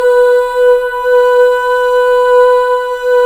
Index of /90_sSampleCDs/Club-50 - Foundations Roland/VOX_xFemale Ooz/VOX_xFm Ooz 2 S